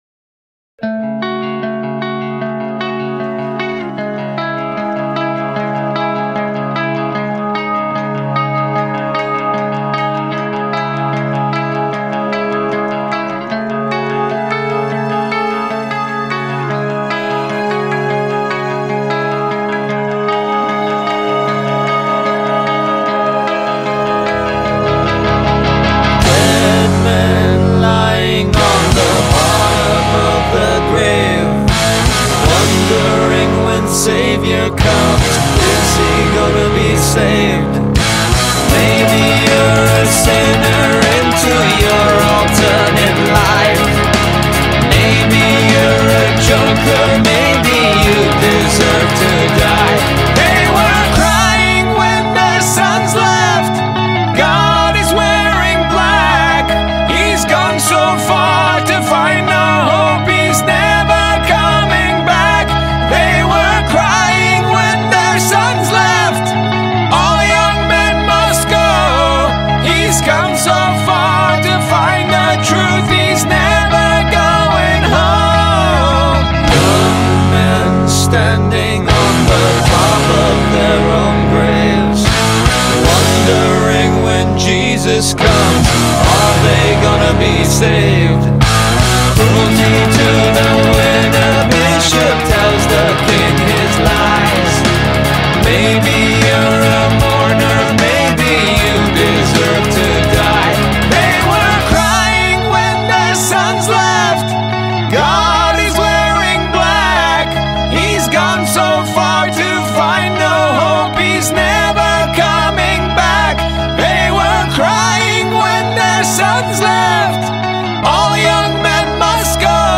Nu Metal, Alternative Metal